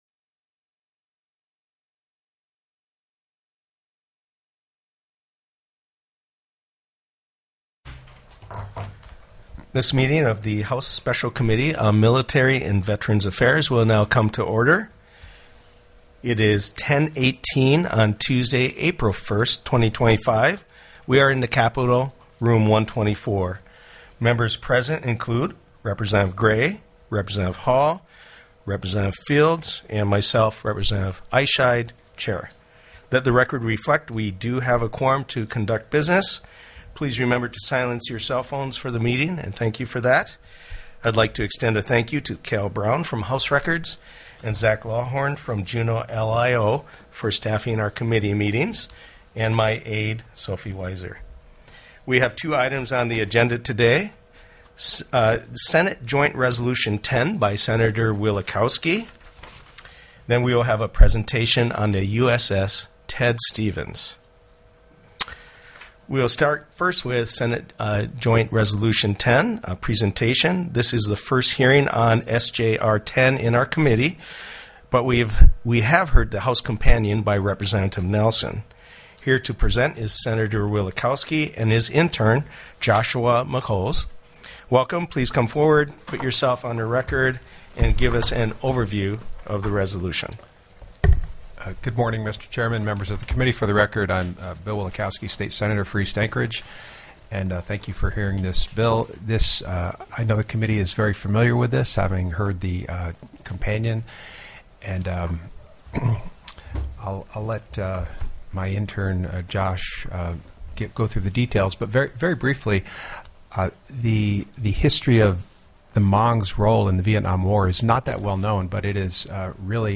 04/01/2025 10:15 AM House MILITARY & VETERANS' AFFAIRS
The audio recordings are captured by our records offices as the official record of the meeting and will have more accurate timestamps.